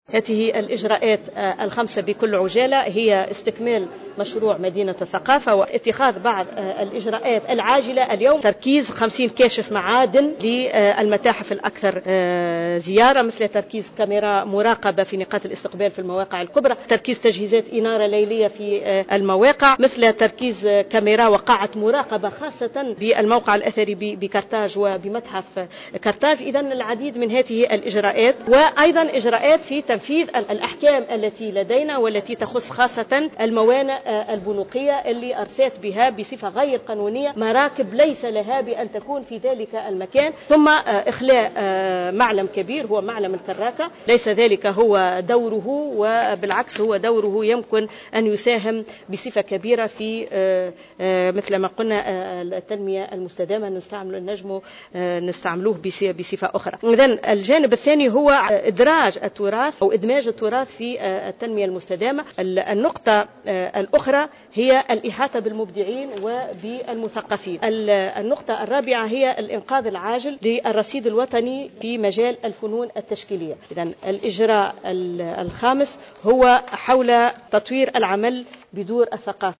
أعلنت وزيرة الثقافة والمحافظة على التراث لطيفة لخضر اليوم الأربعاء 15 أفريل 2015 خلال لقاء صحفي انتظم بقصر الحكومة بالقصبة لتقديم الإجراءات ذات الأولوية خلال ال 100 يوم من برنامج عمل الوزارة عن استكمال إنجاز مشروع مدينة الثقافة المعطل منذ نوفمبر 2011.